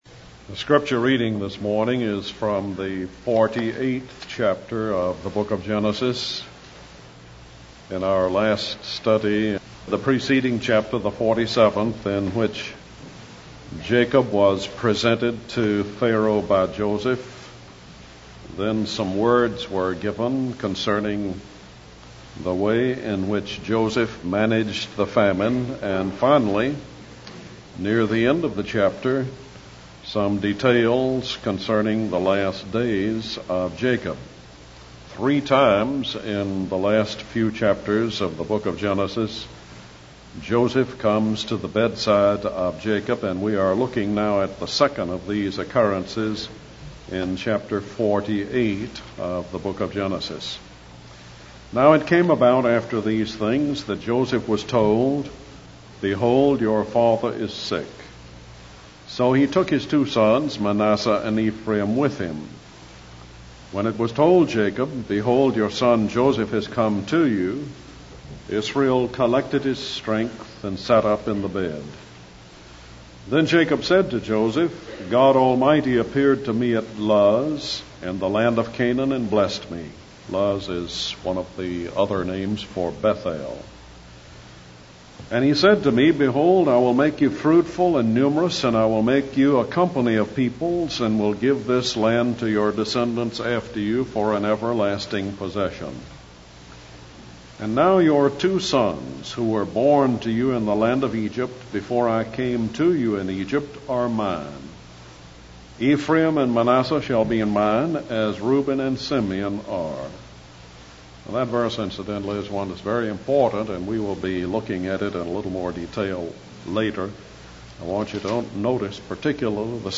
In this sermon, the speaker discusses the importance of taking a positive perspective on life, even in the midst of trials and difficulties. He uses the example of Jacob, who looks back on his life and sees how God has provided for him.